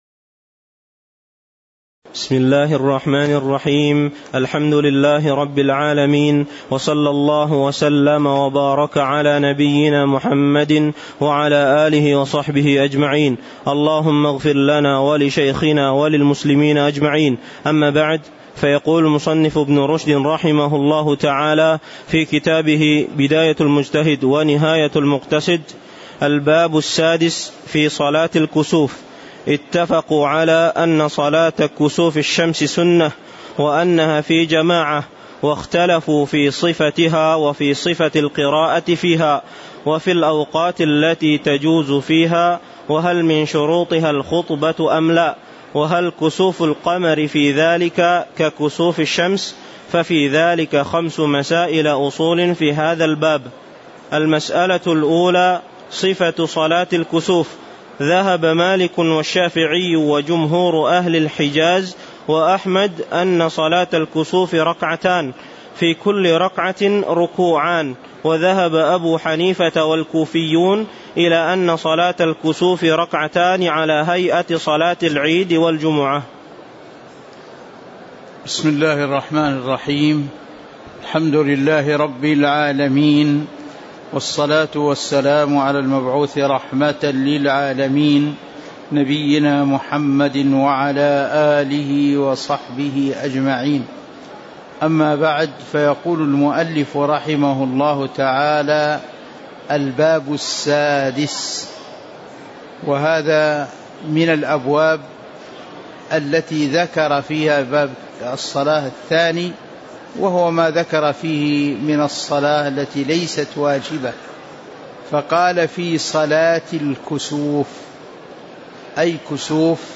تاريخ النشر ٢٩ جمادى الآخرة ١٤٤٤ هـ المكان: المسجد النبوي الشيخ